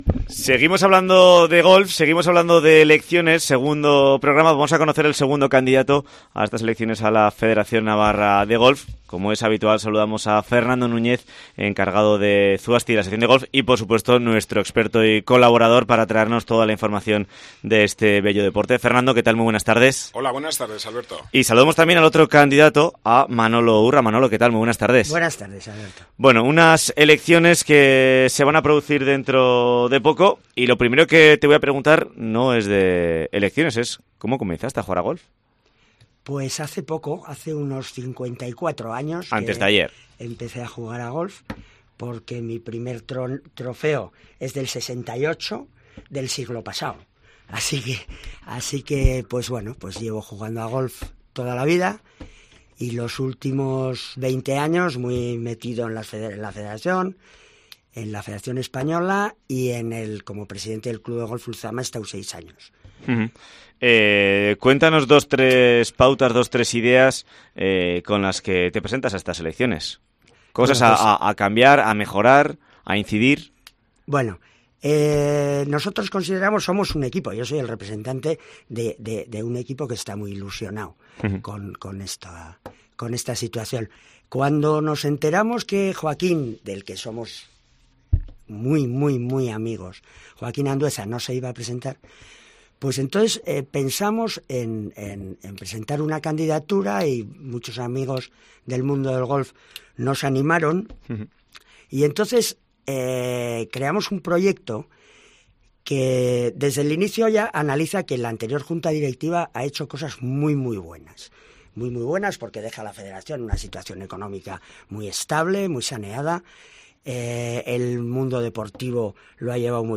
Elecciones Federación Golf - Entrevista